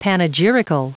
Prononciation du mot panegyrical en anglais (fichier audio)
Prononciation du mot : panegyrical